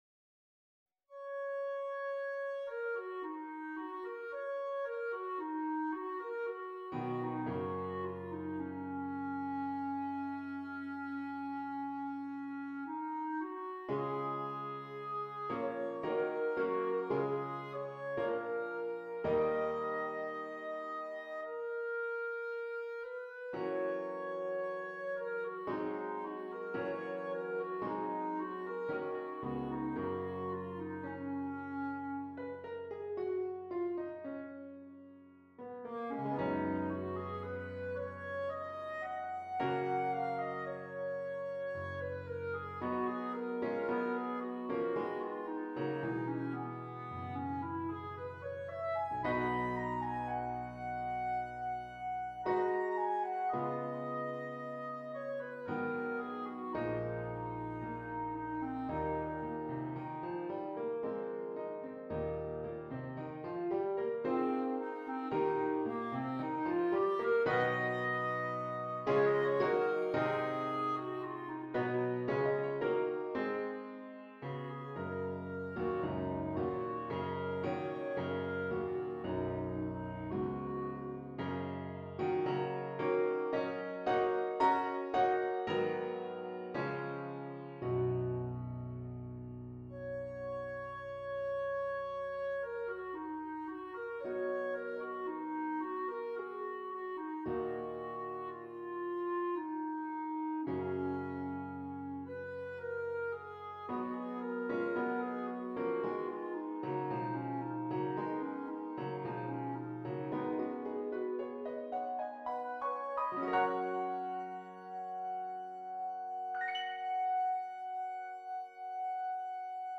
Clarinet and Keyboard
with its impressionistic and progressive harmonies